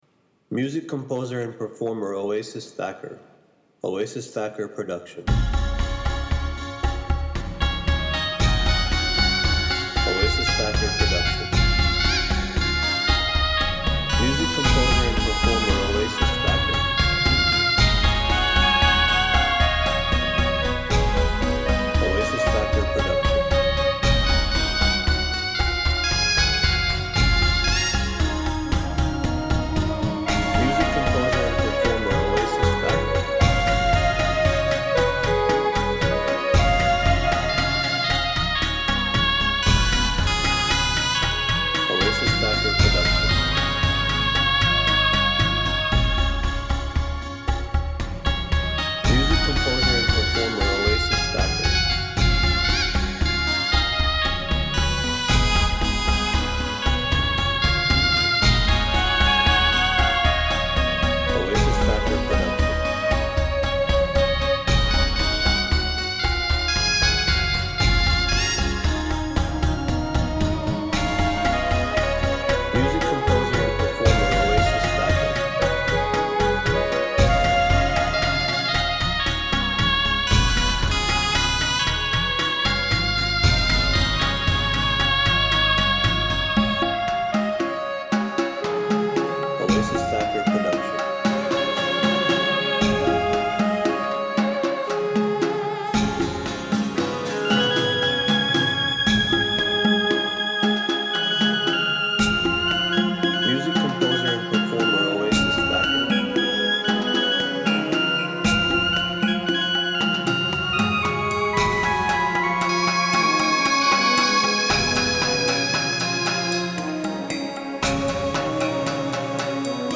Instrumental Music
Orchestra Arrangement and Performanence